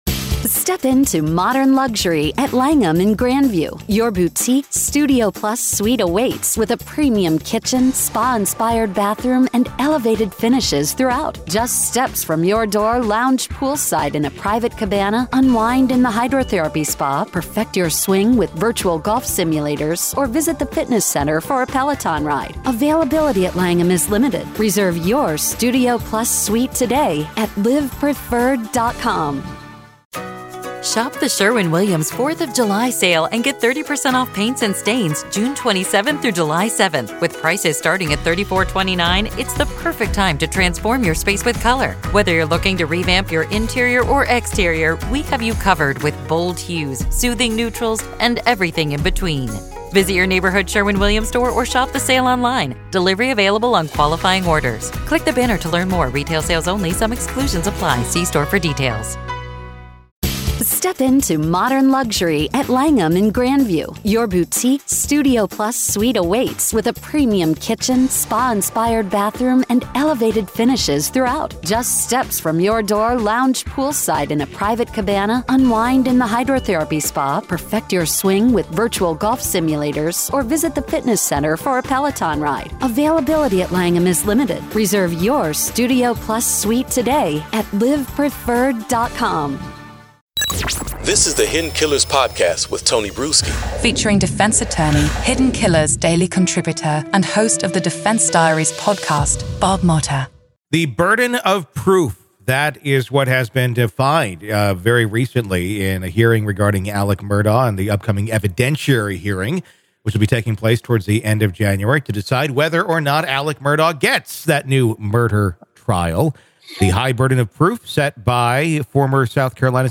a defense attorney and podcast host